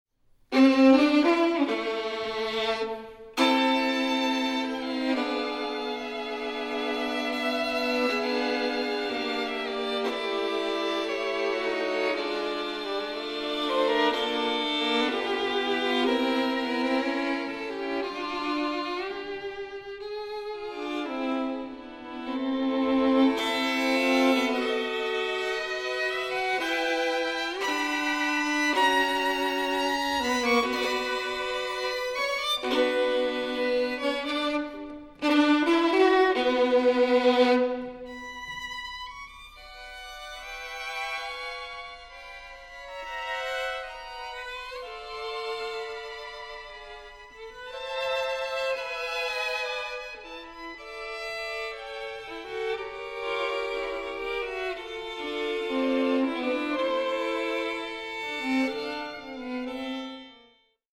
Poco lento maestoso. Allegro fermo 11'38